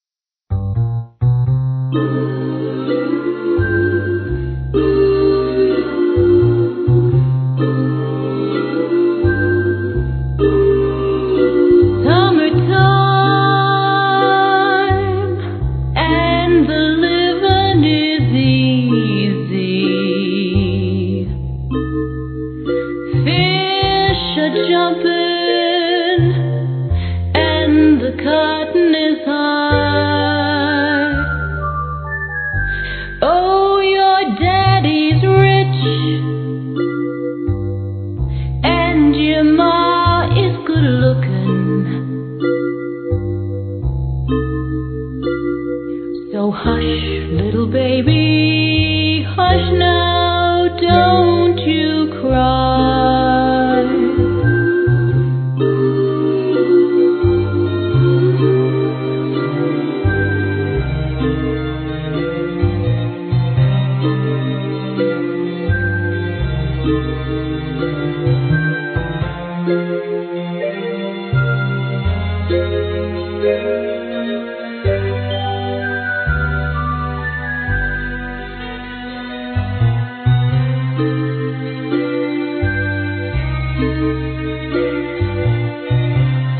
Celebrity singing